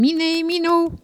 Cri pour appeler le chat ( prononcer le cri )
Catégorie Locution